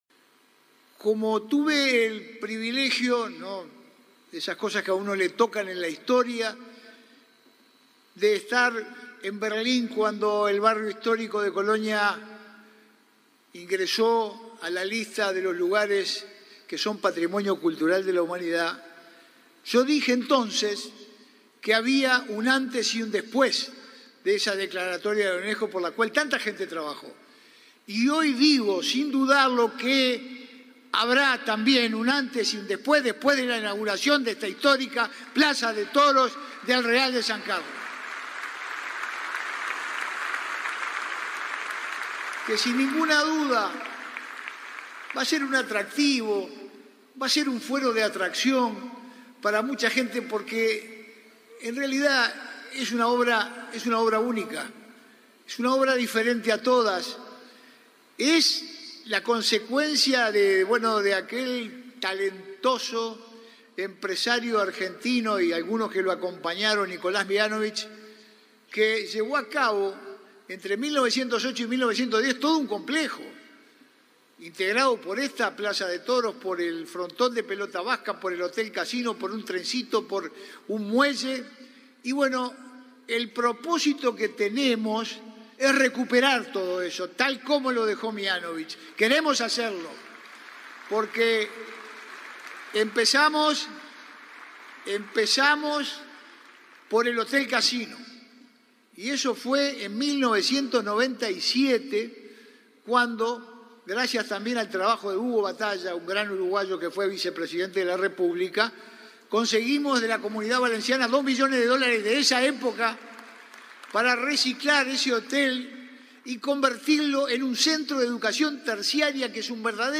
Reinauguración de la Plaza de Toros Real de San Carlos 10/12/2021 Compartir Facebook X Copiar enlace WhatsApp LinkedIn Este jueves 9, el intendente de Colonia, Carlos Moreira; el director de la Oficina de Planeamiento y Presupuesto, Isaac Alfie, y el ministro de Turismo, Tabaré Viera, participaron en el acto de reinauguración de la Plaza de Toros Real de San Carlos, en Colonia.